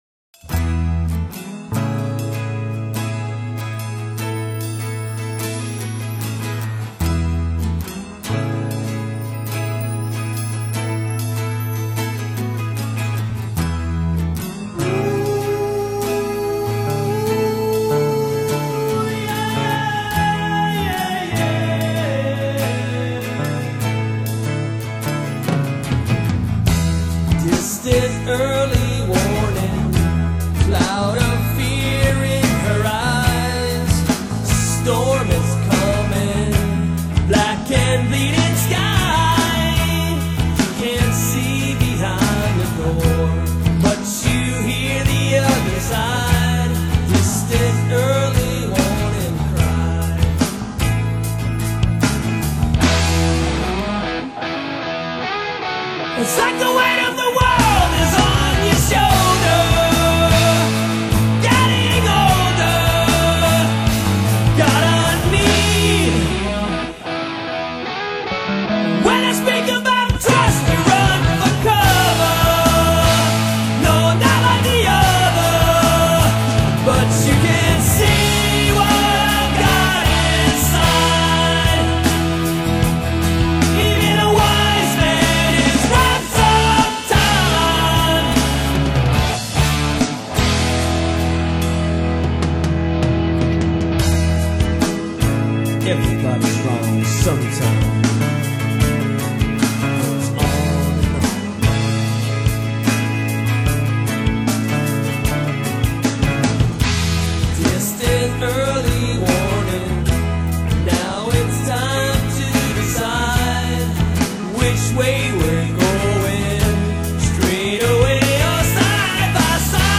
Alternative Rock (Full Band)